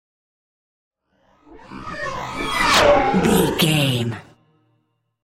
Sci fi vehicle pass by super fast
Sound Effects
futuristic
intense
pass by
vehicle